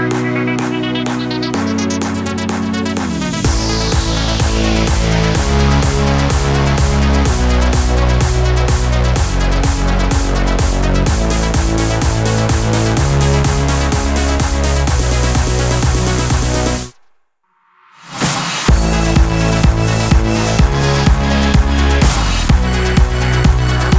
Remix Dance